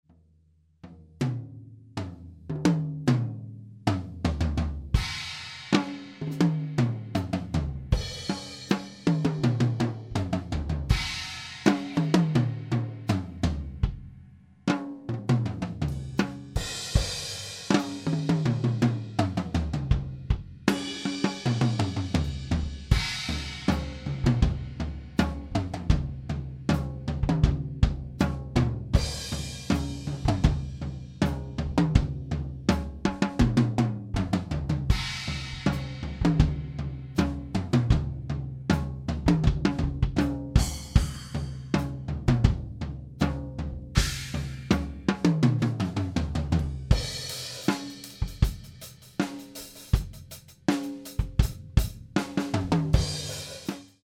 Mit Overhead, Snare und Bassdrum
Im Proberaum habe ich mit einem Behringer xr18 und CakeWalk aufgenommenen.
Ich finde den Klang des Wahan ganz erfrischend gut - ich bilde mir ein, den etwas knalligeren Charakter des Acryls zu hören!
Bei der Wahan-Datei fällt auf: starkes Anschlagsgeräusch (typisch für klare Felle);